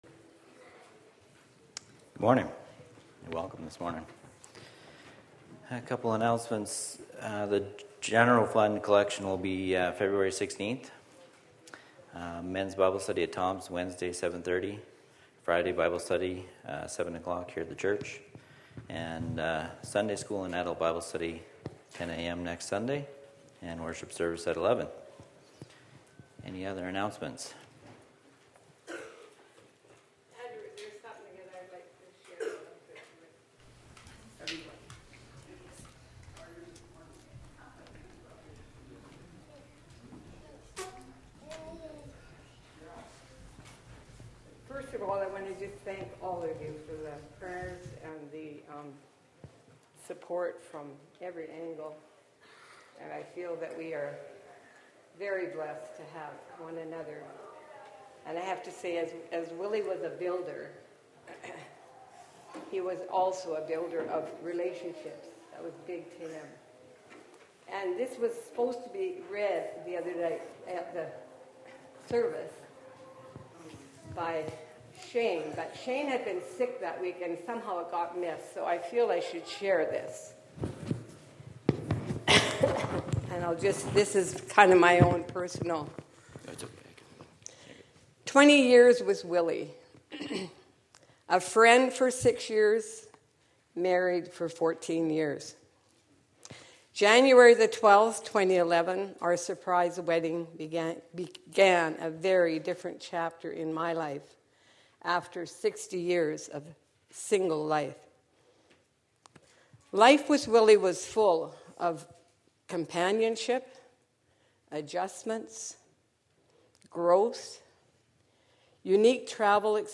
Sunday worship